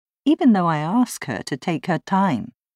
今日の最後の学習は、女性の台詞から、こちらの部分です。
理由は、her が「ハー」ではなく、「アー」 と発音されているからです。